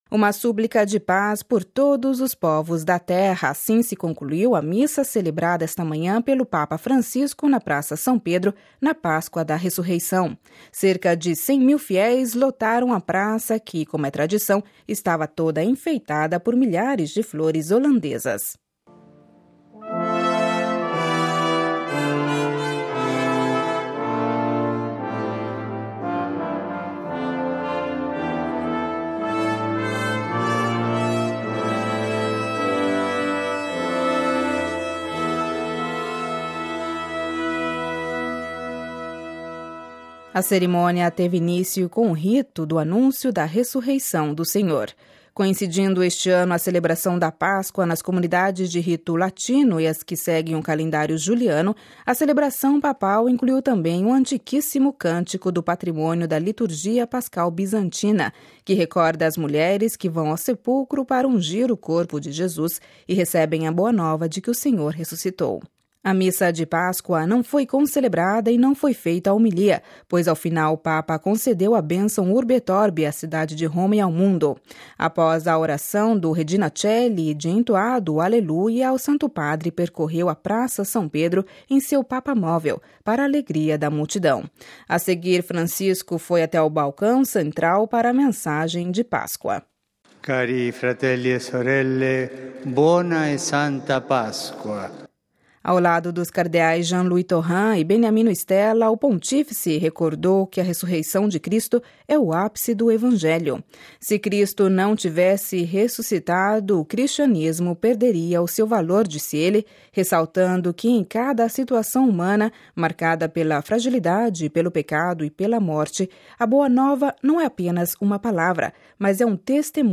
A seguir, Francisco foi até o balcão central da Basílica Vaticana para a mensagem de Páscoa.